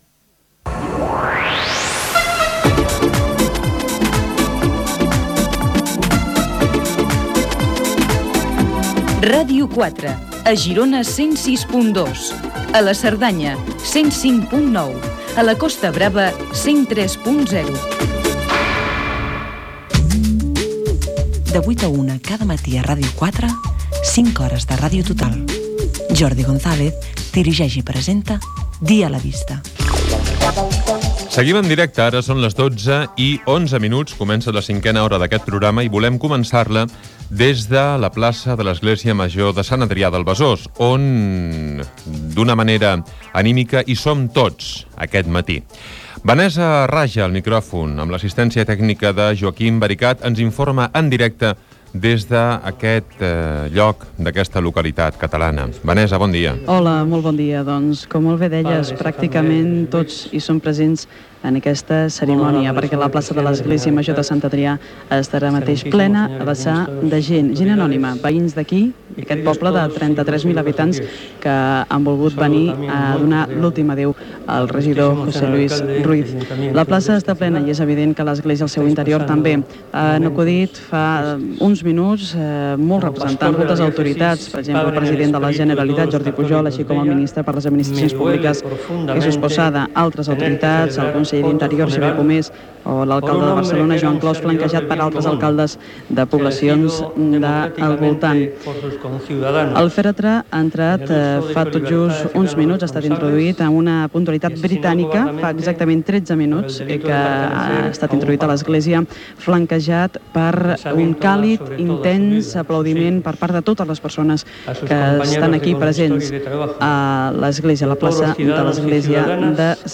Indicatiu de l'emissora amb freqüències
Gènere radiofònic Info-entreteniment